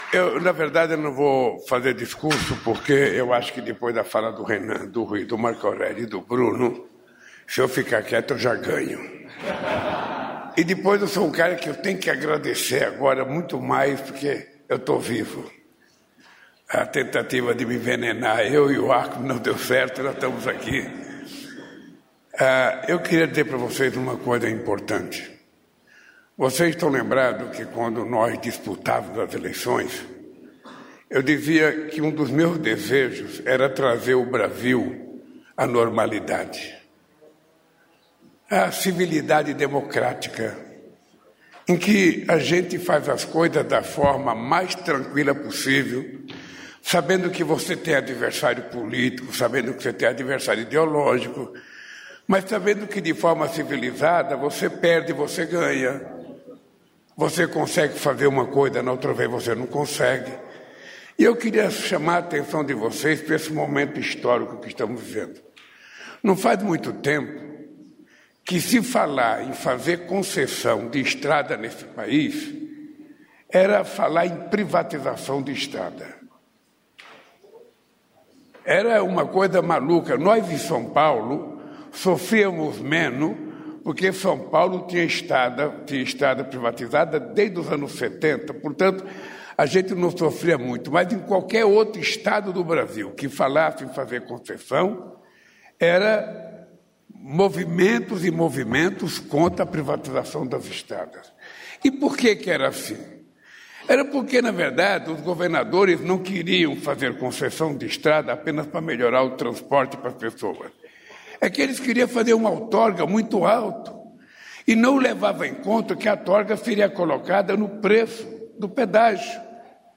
Íntegra do discurso do presidente Luiz Inácio Lula da Silva na cerimônia que marca retorno do manto Tupinambá ao Brasil, nesta quinta-feira (12), no Museu Nacional, no Rio de Janeiro.